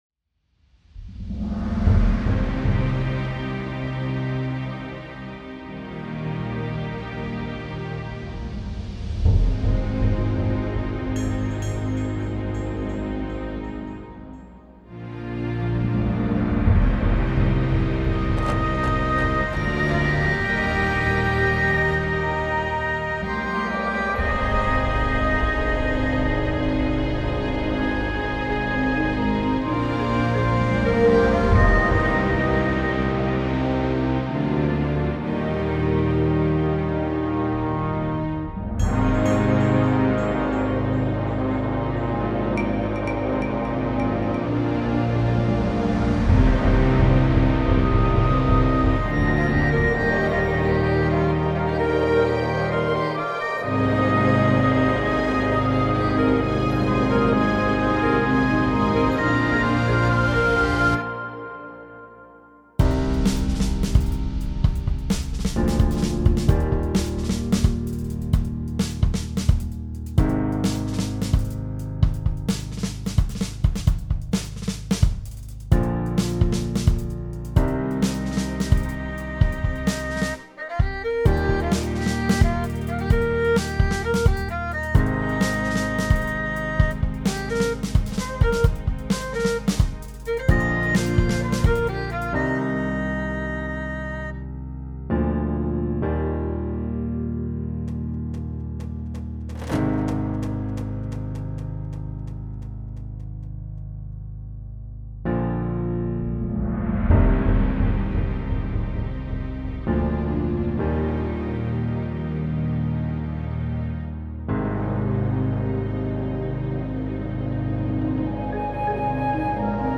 Epic Orchestra+Drum music
orchestra music_1.mp3